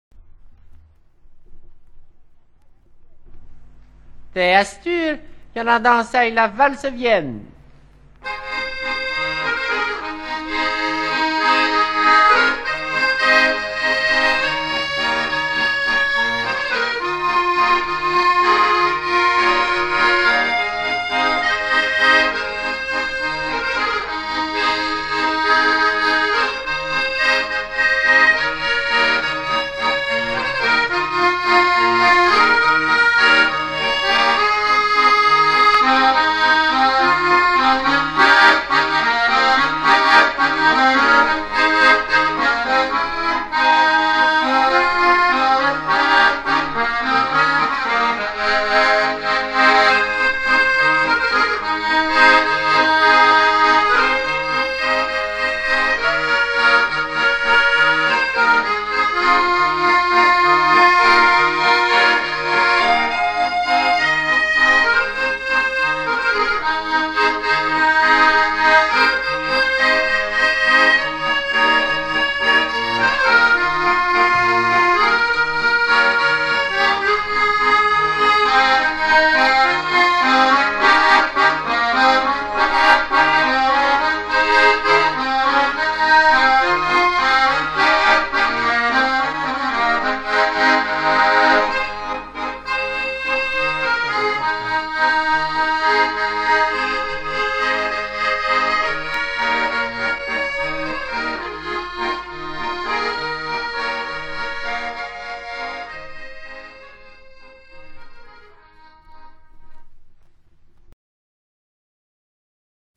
(valse)